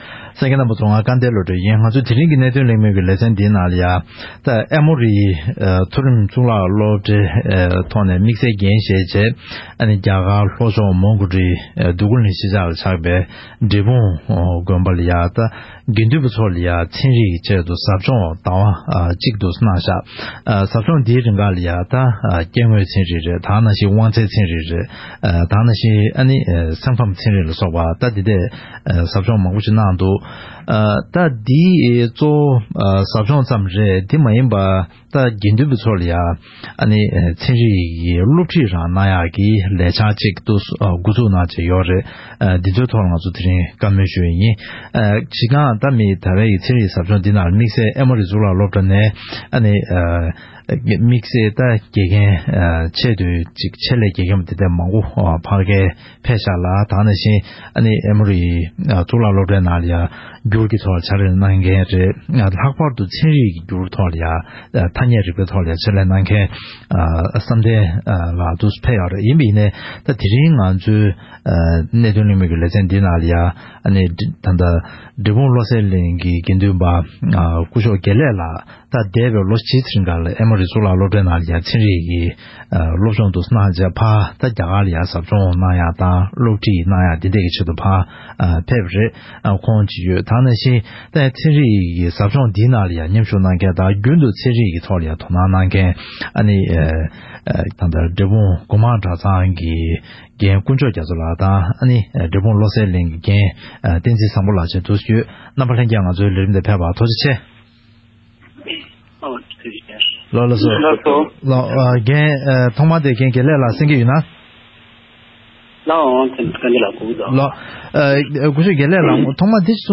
༄༅། །ཐེངས་འདིའི་གནད་དོན་གླེང་མོལ་གྱི་ལས་རིམ་ནང་།